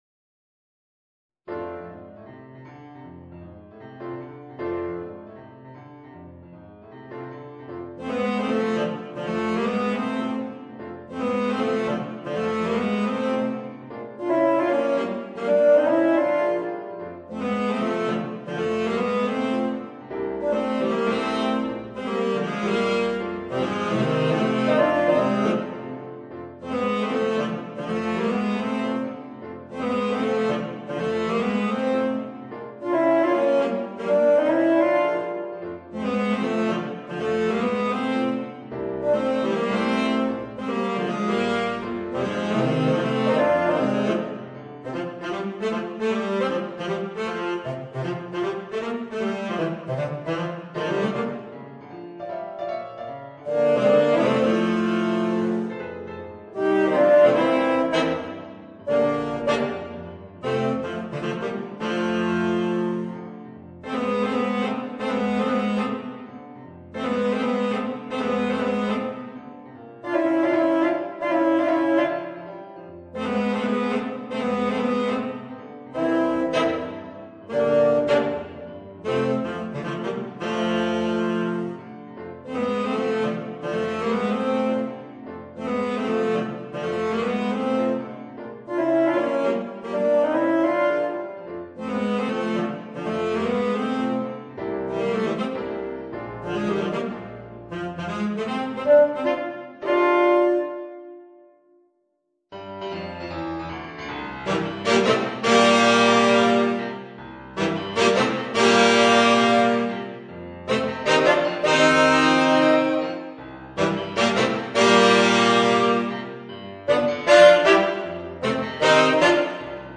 Voicing: 2 Tenor Saxophones and Piano